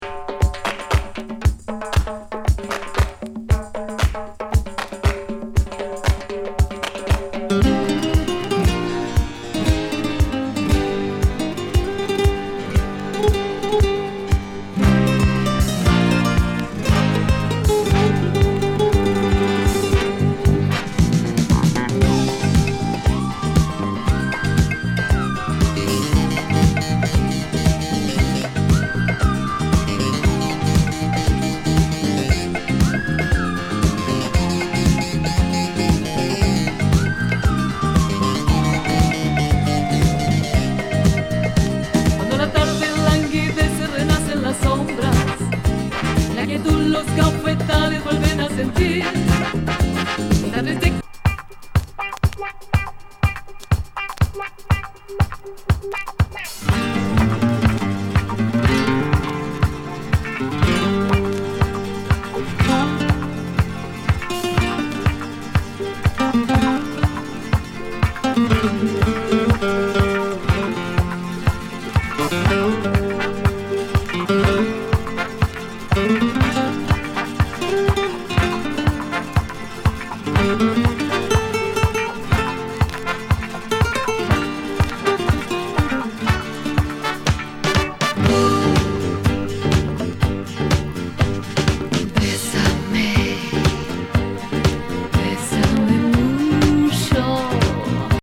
ドイツ産コズミック・ラテン・ディスコ！